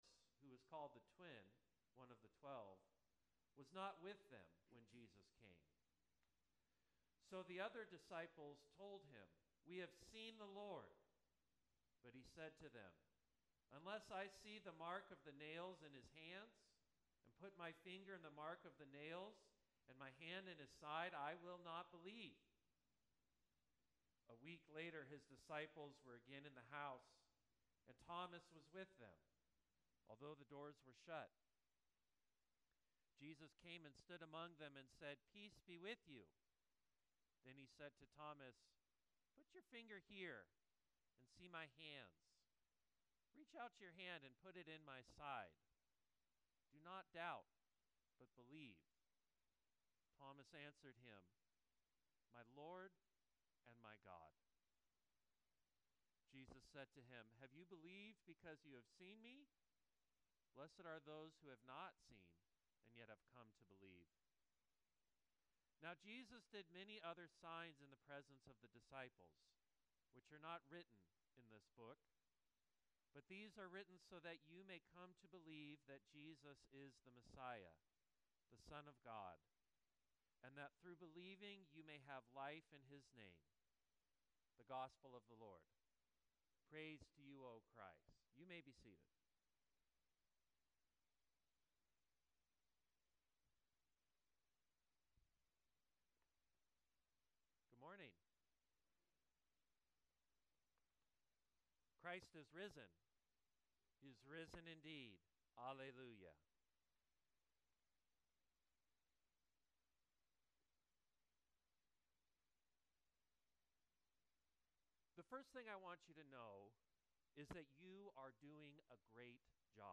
Sermon 04.27.25